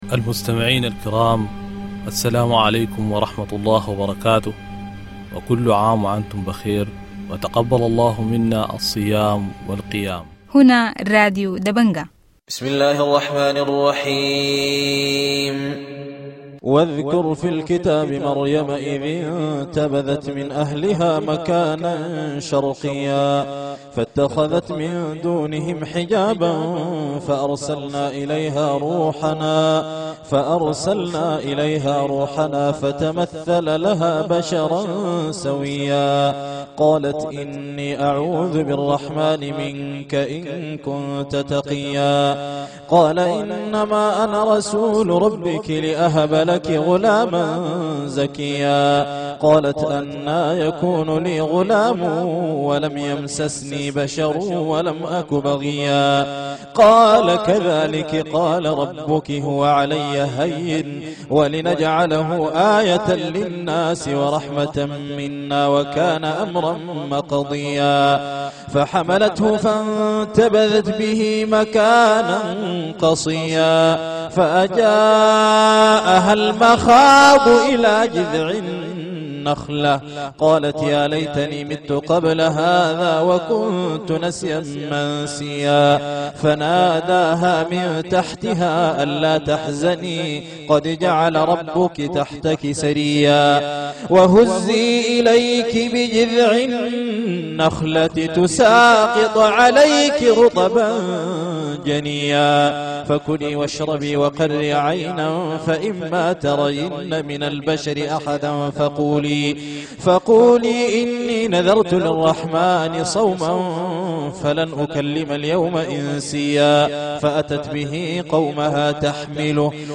Evening news 26 June - Dabanga Radio TV Online